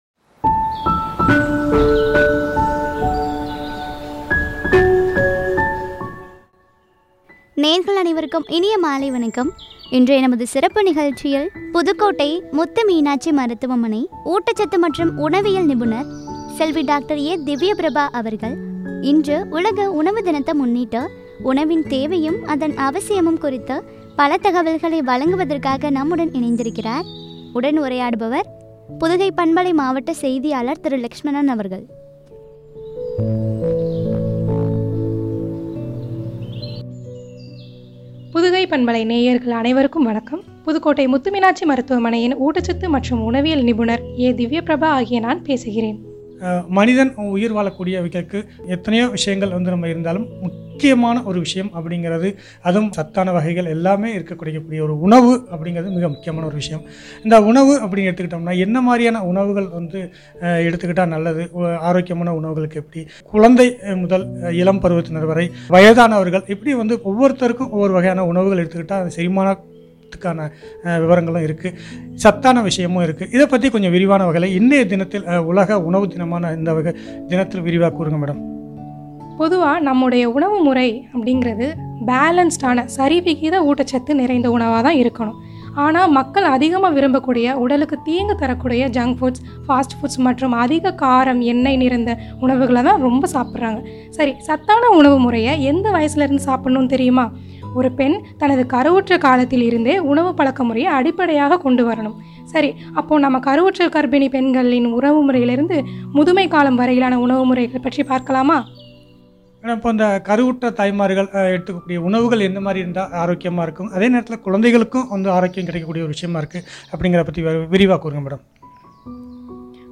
உணவின் தேவையும், அவசியம் பற்றிய உரையாடல்.